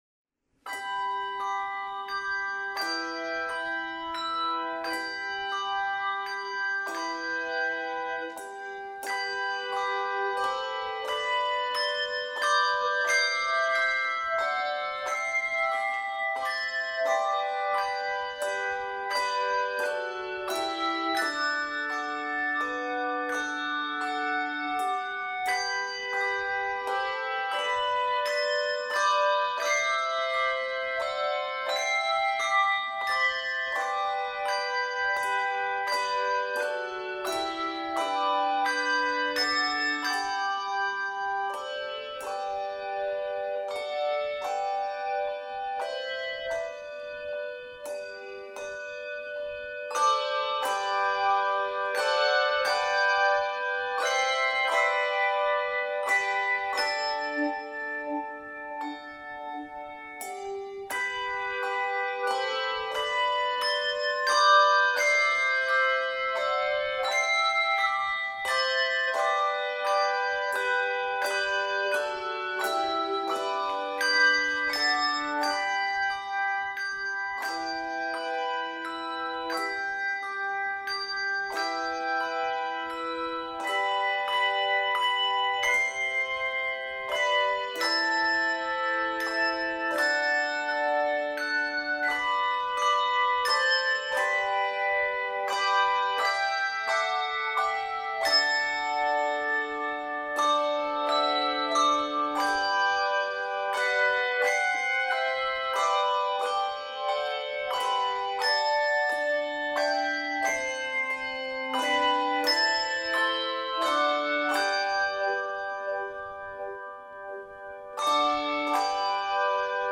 LV and echo techniques are used effectively